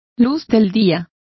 Complete with pronunciation of the translation of daylights.